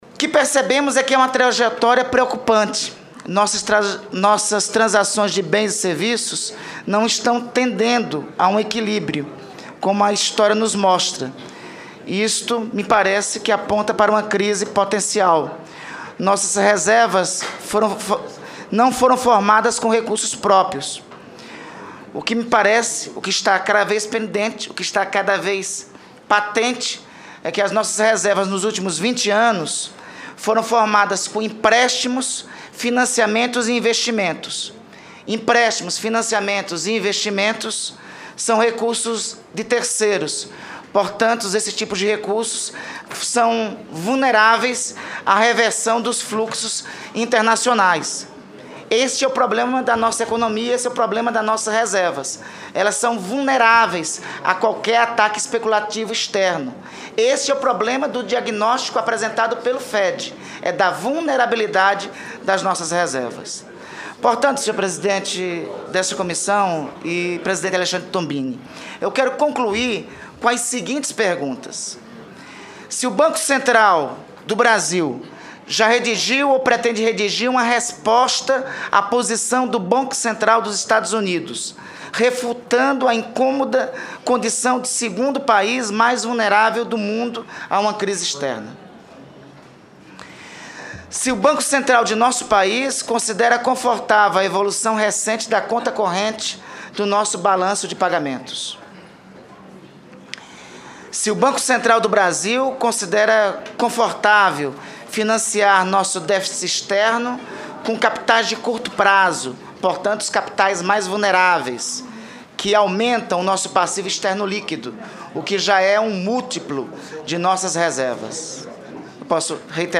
O presidente do Banco Central, Alexandre Tombini, participa de audiência pública na Comissão de Assuntos Econômicos, na manhã desta terça-feira, para falar sobre a política monetária do país.